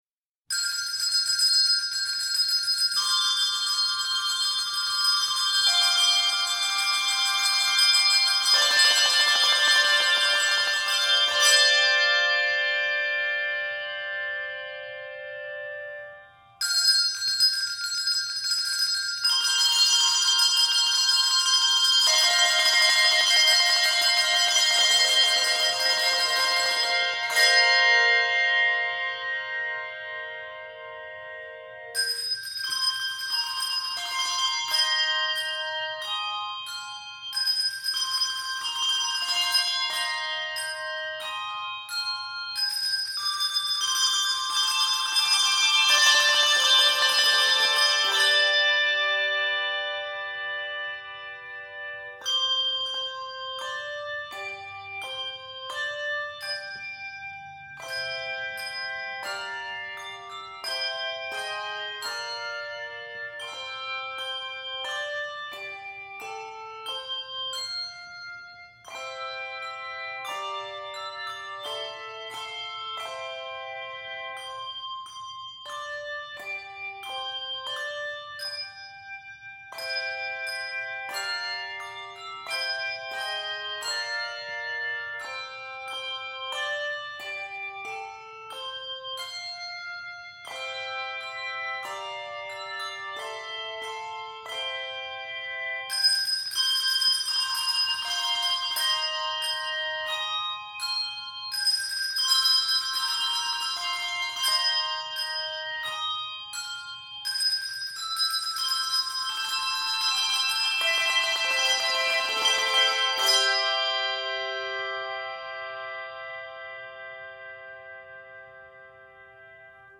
which is set in 3/4 time in simple, chordal rhythms.
imitative in texture, and ends with a flourish.